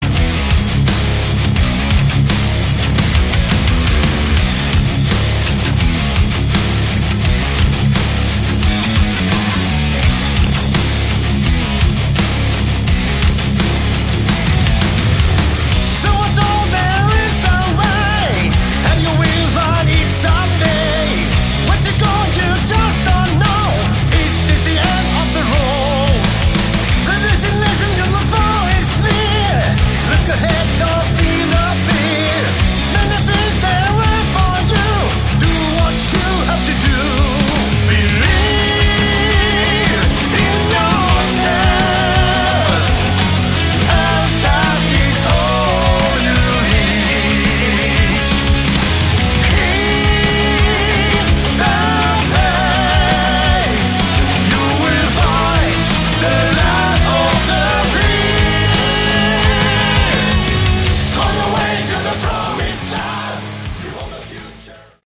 Category: Melodic Metal
Vocals
Bass
Drums
Guitar